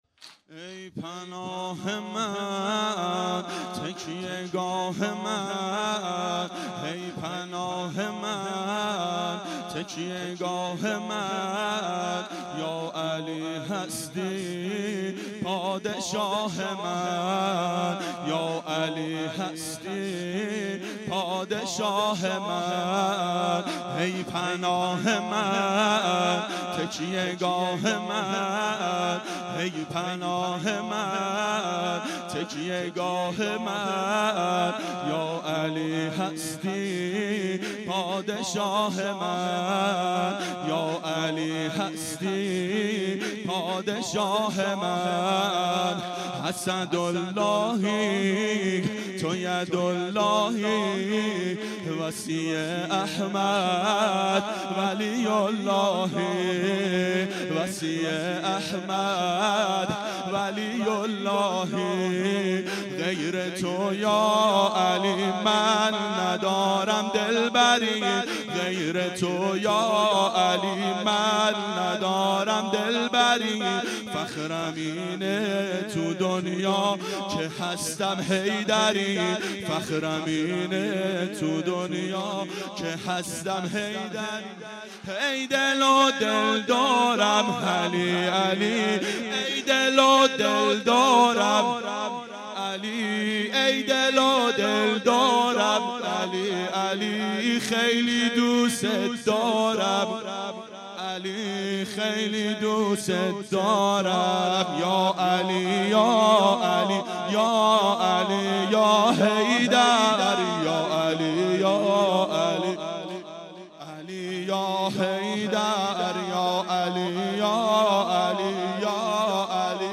دهه اول صفر سال 1392 هیئت شیفتگان حضرت رقیه سلام الله علیها